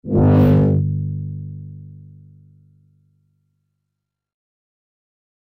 Звуки магнита
Звук магнитного поля возле металлического предмета